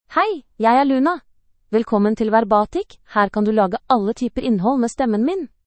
Luna — Female Norwegian Bokmål AI voice
Luna is a female AI voice for Norwegian Bokmål (Norway).
Voice sample
Listen to Luna's female Norwegian Bokmål voice.
Female